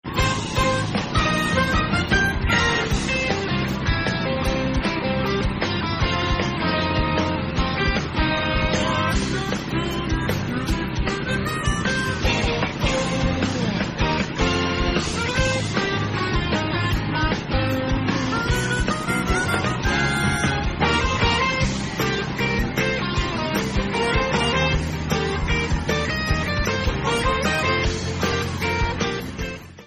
Copyrighted music sample